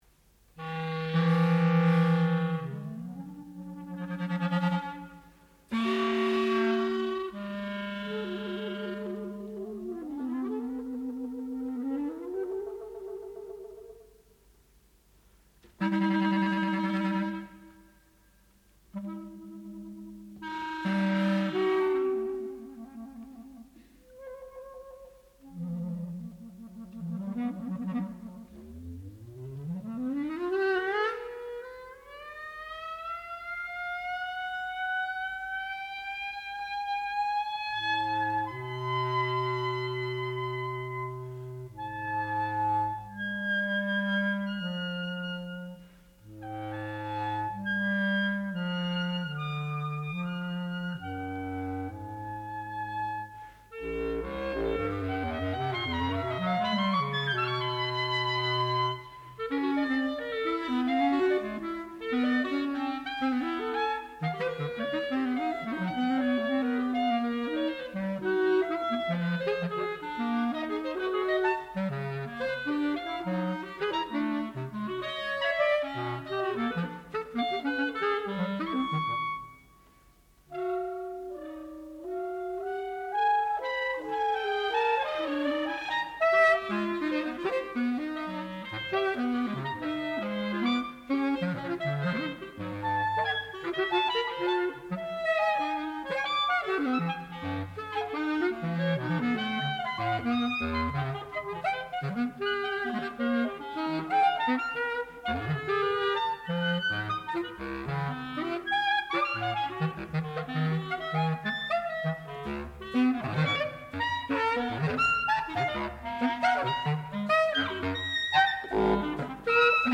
Disturbances (1972), for brass sextet
sound recording-musical
classical music